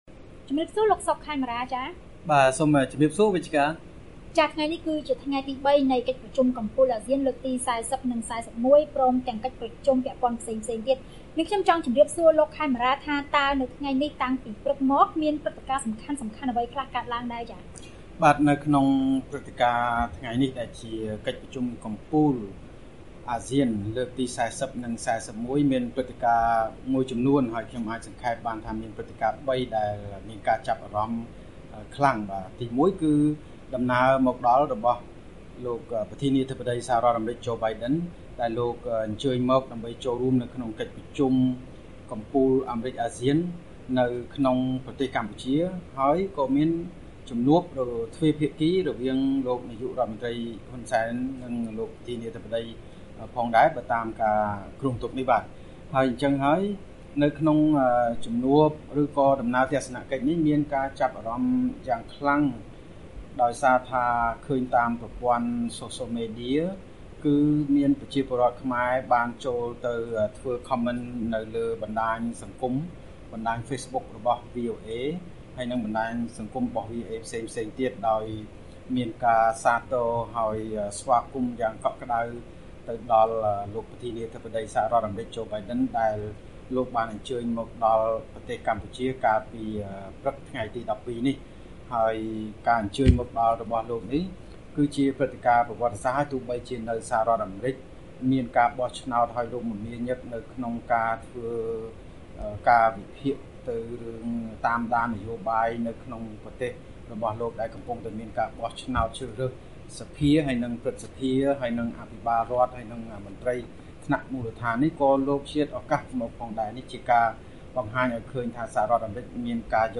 កិច្ចសន្ទនា VOA៖ ប្រធានាធិបតីអាមេរិកនិងមេដឹកនាំអាស៊ានជួបប្រជុំគ្នានៅកម្ពុជាលើបញ្ហាប្រឈមបន្ទាន់ៗ